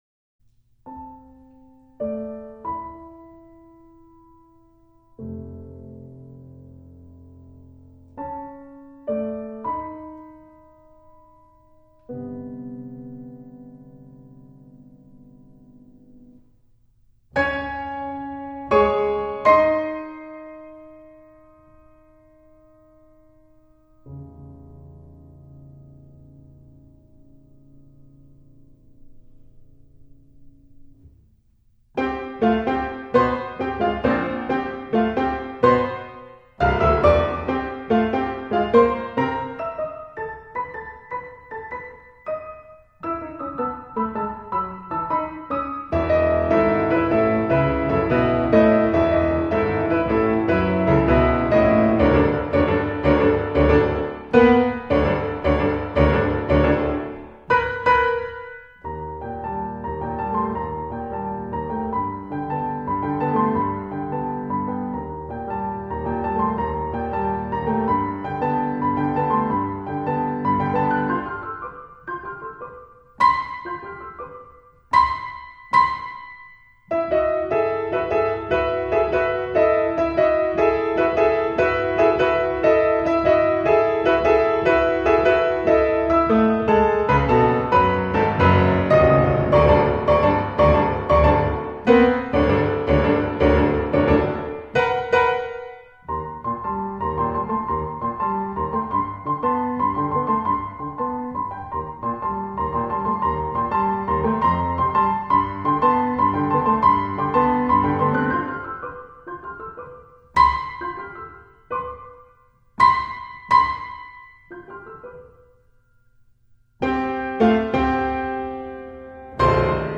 7min instrumentation: Piano premiere